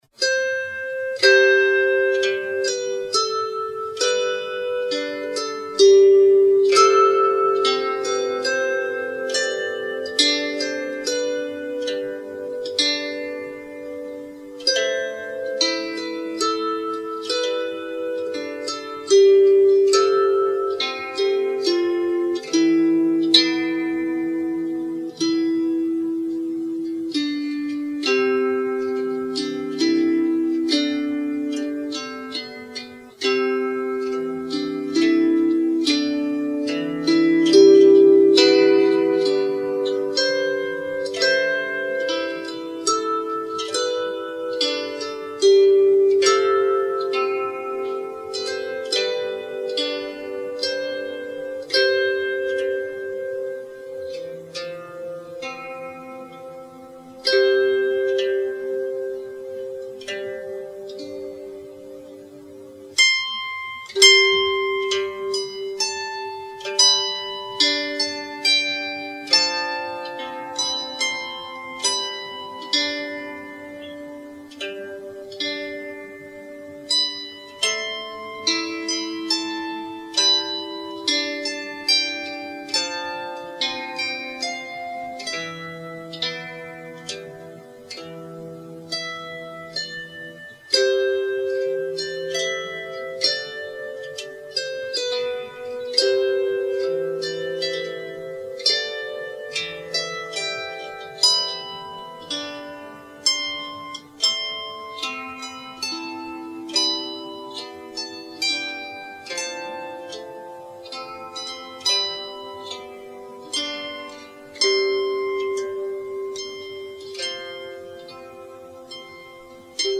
“Wings of Autumn,” original song on zither.
Download Zither Mp3